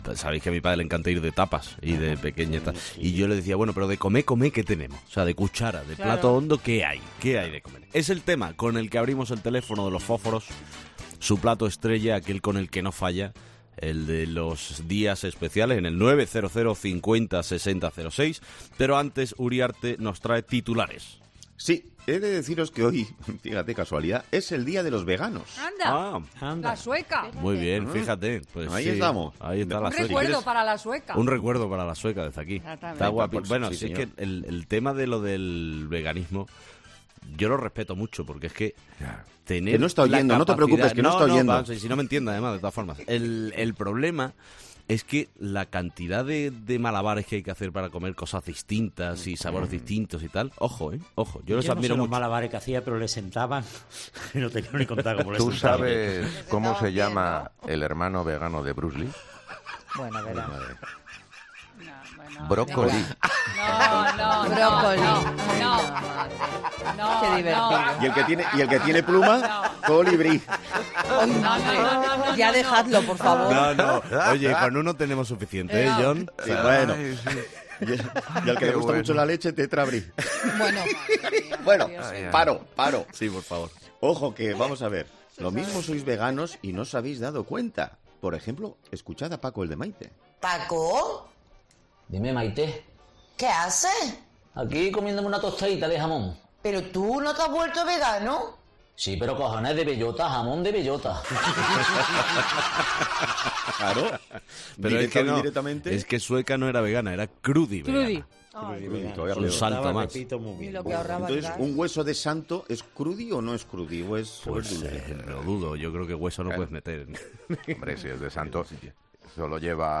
Y es que, en ese momento de tertulia radiofónica se ha abordado la complejidad que supone que existan varios 'días de', donde se celebran o recuerdan cosas de lo más curiosas.